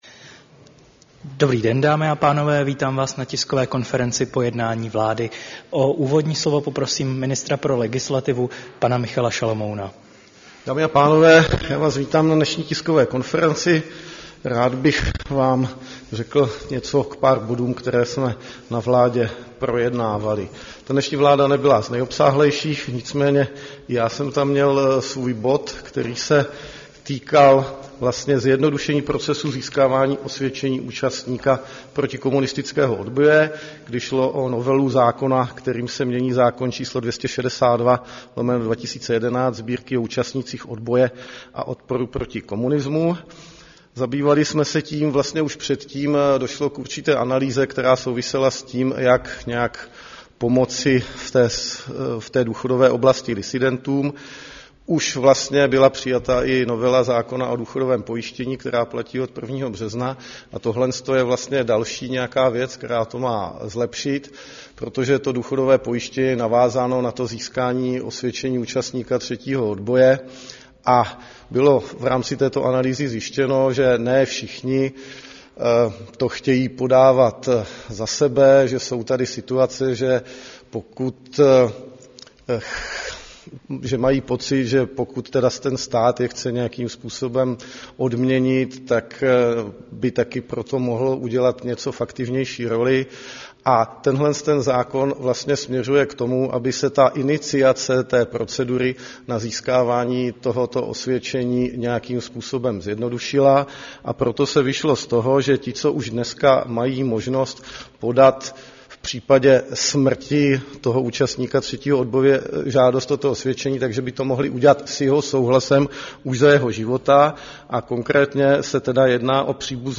Tisková konference po jednání vlády, 5. června 2024